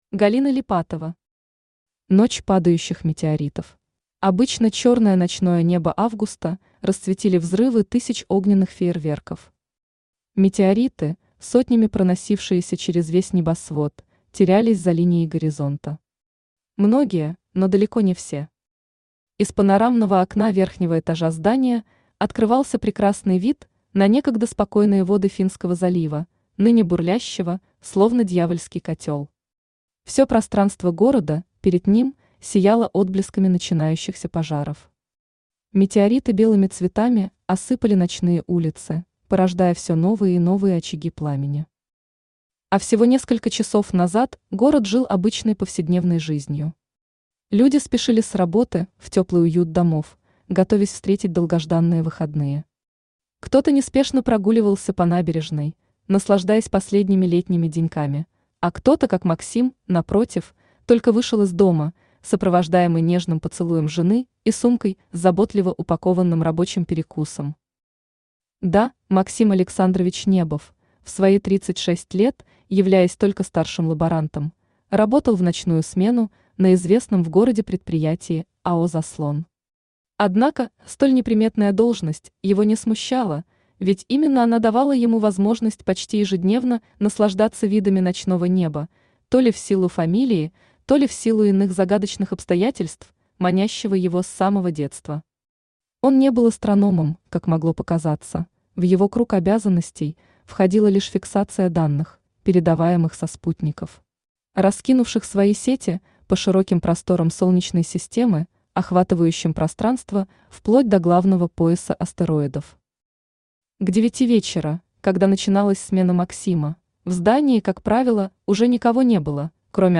Аудиокнига Ночь падающих метеоритов | Библиотека аудиокниг
Aудиокнига Ночь падающих метеоритов Автор Галина Липатова Читает аудиокнигу Авточтец ЛитРес.